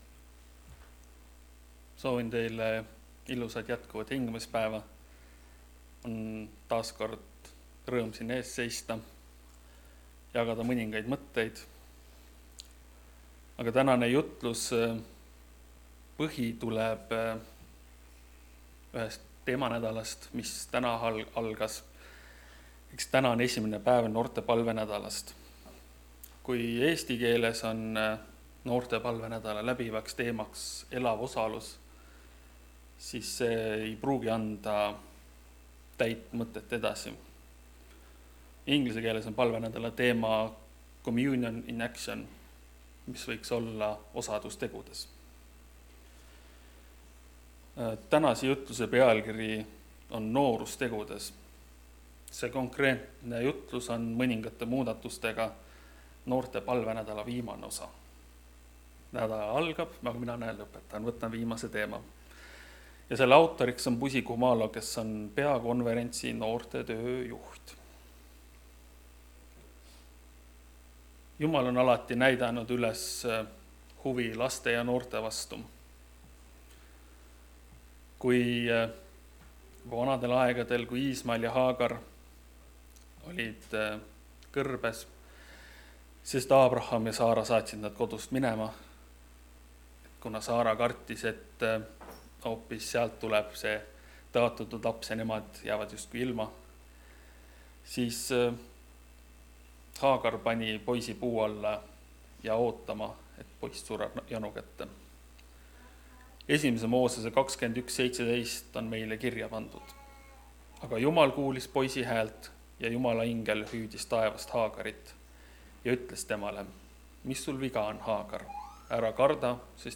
Jutlus on valminud 2026 aasta noorte palvenädala viimase loengu ainetel.